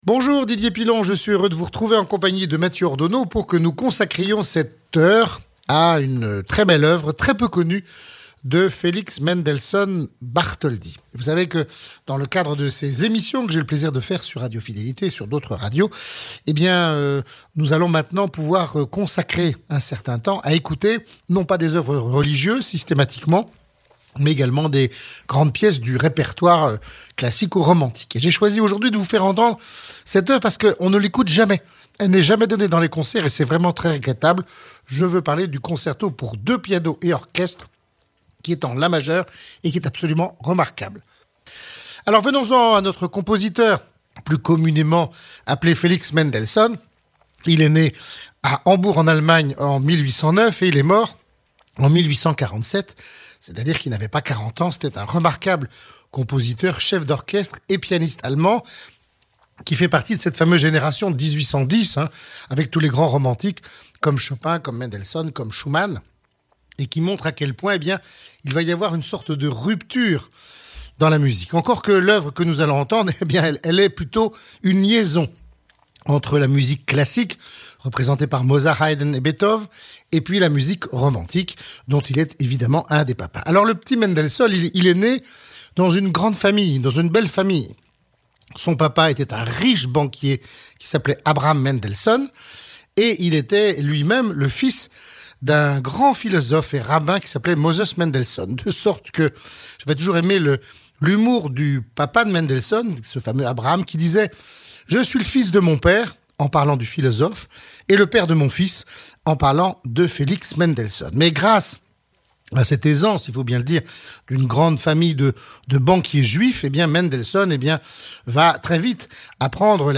DP-Felix Mendelssohn - Concerto pour 2 pianos et orchestre